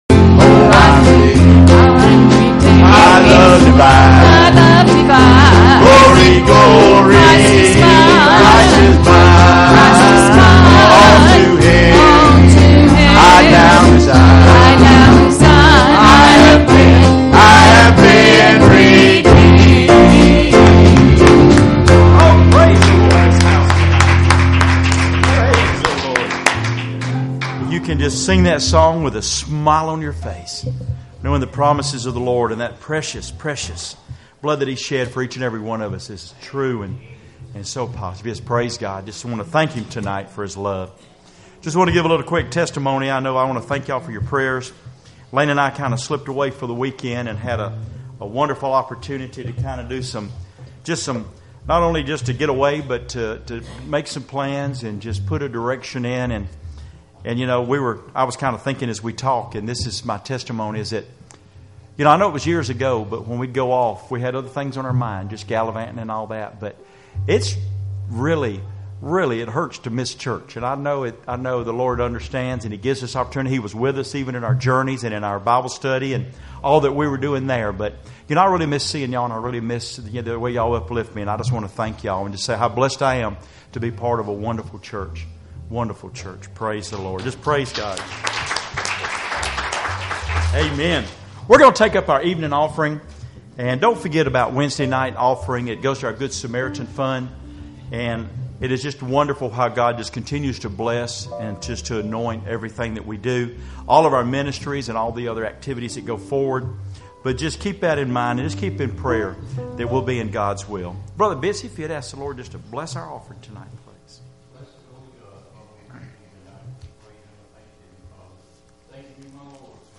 Passage: Acts 3:11 Service Type: Wednesday Evening Services Topics